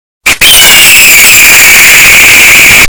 Eagle Earrape Loud Asf Sound Effect Download: Instant Soundboard Button